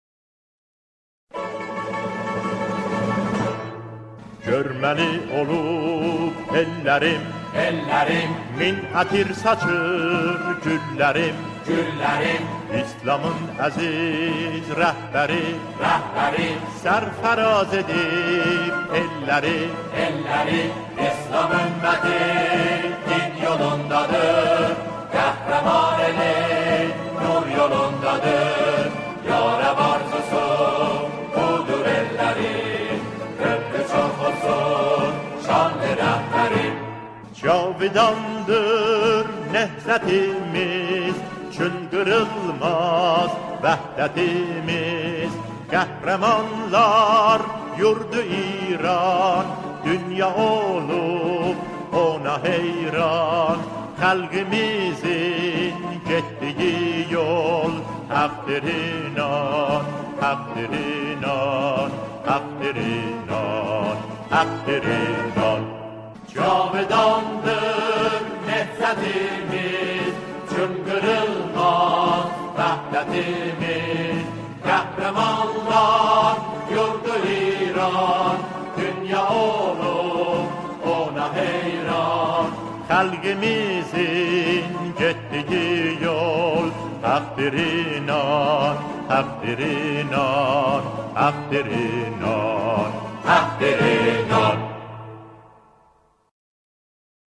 آنها در این قطعه، شعری آذری را همخوانی می‌کنند.